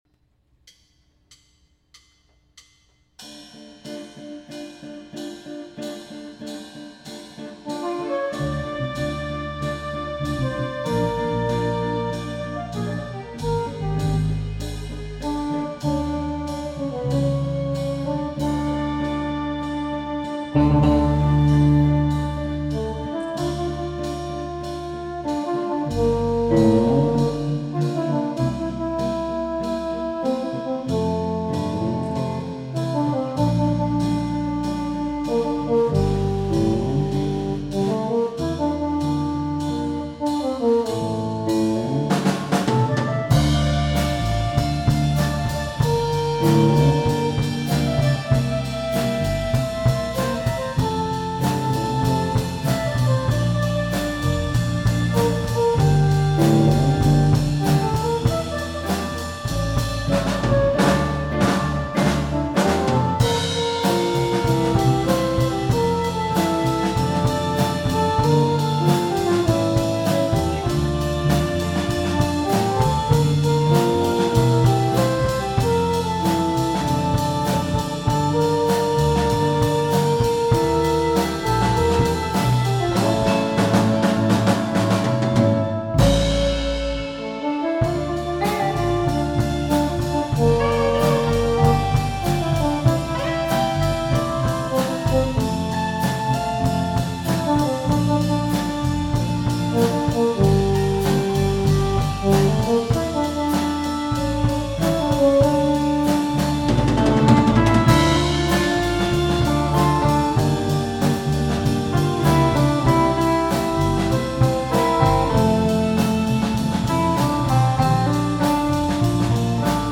担当 キーボード